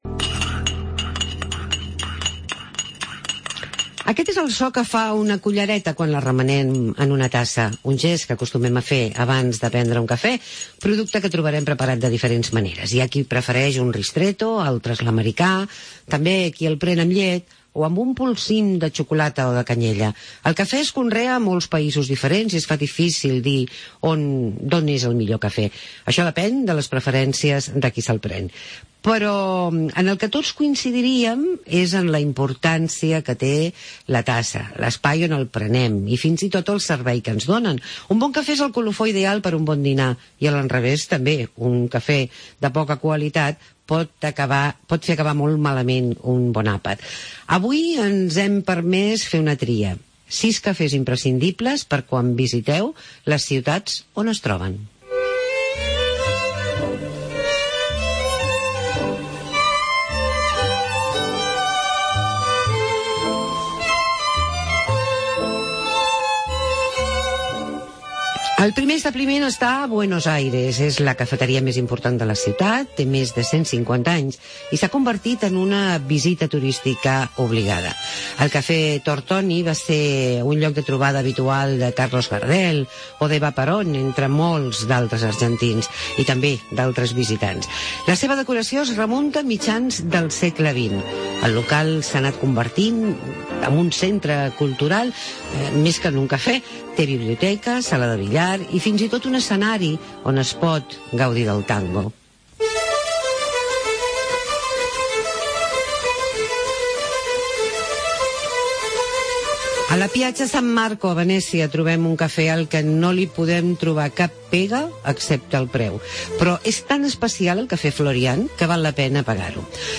Fem un petit reportatge sobre els diferents cafès del mon